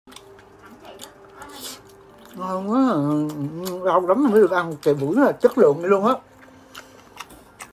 Âm thanh độc đáo, được ưa chuộng rộng rãi, giúp tăng sức hút cho nội dung.